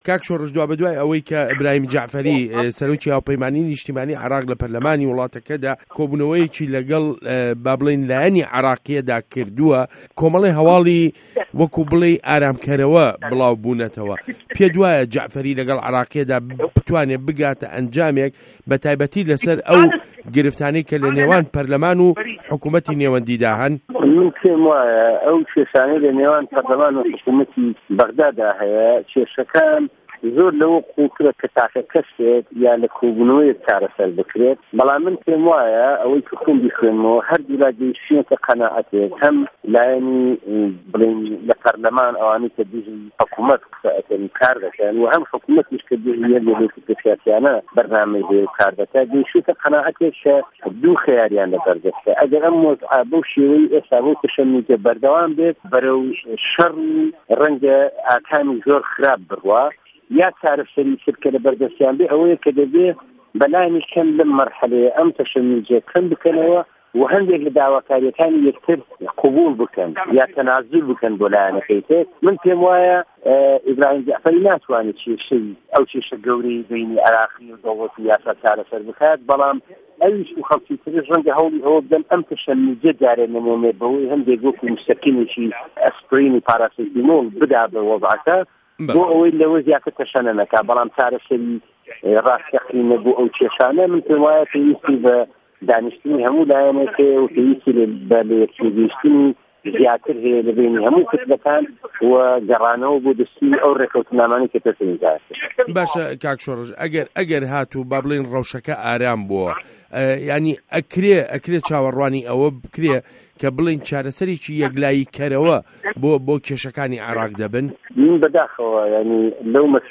وتووێژ له‌گه‌ڵ شۆڕش حاجی